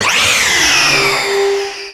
P3D / Content / Sounds / Cries / 800_dawn-wings.wav